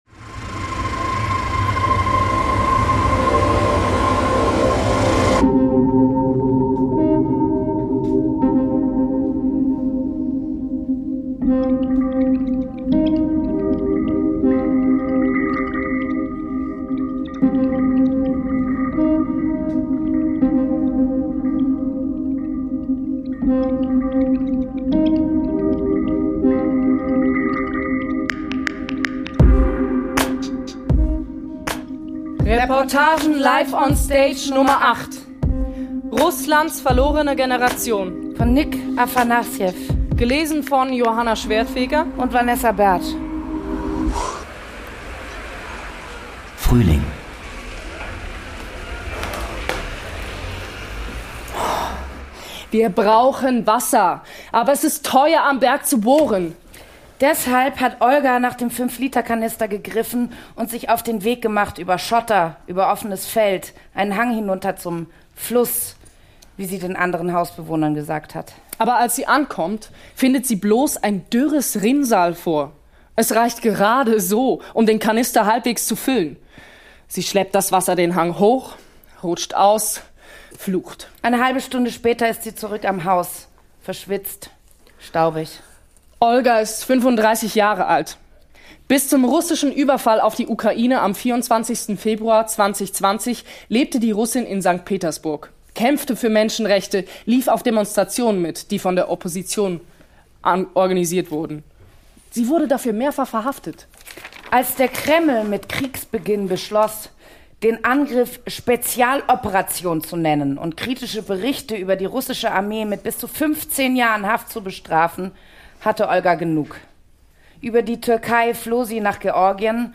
Eine WG gegen den Krieg ~ Reportagen Live on Stage Podcast